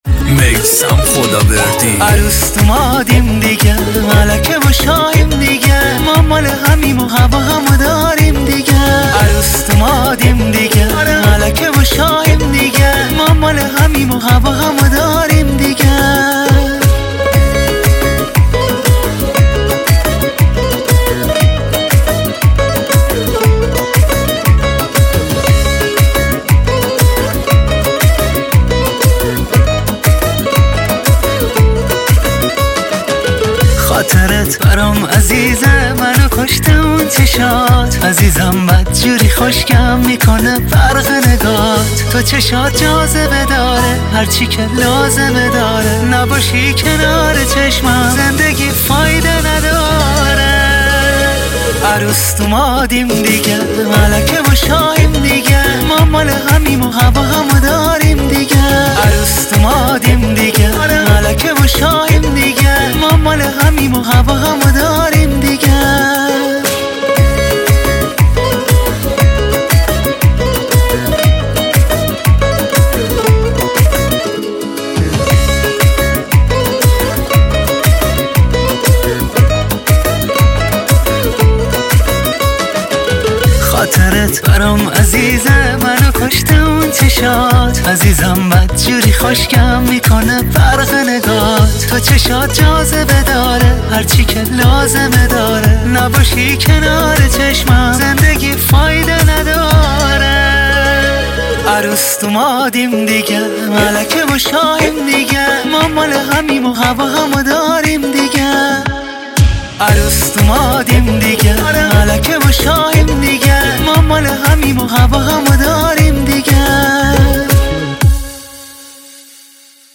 آهنگهای پاپ فارسی
روزشبتون شادباد عالیه انرژی میده👏👏👏👏👏